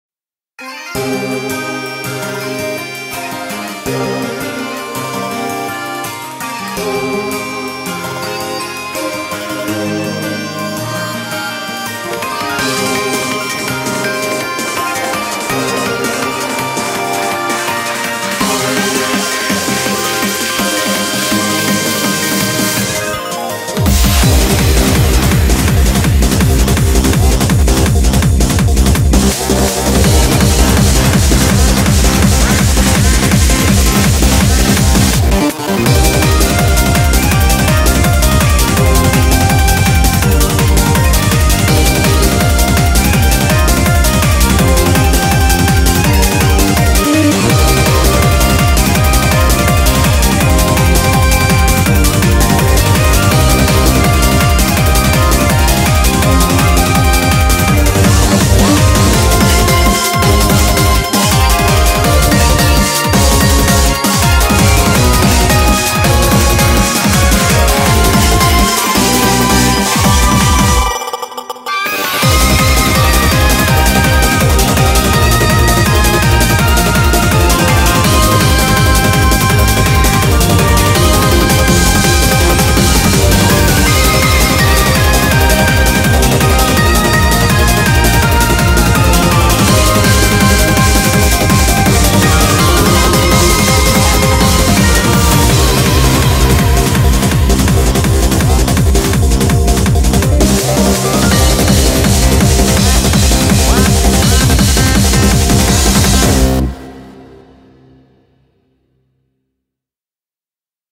BPM165
Audio QualityPerfect (Low Quality)